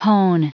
Prononciation du mot : hone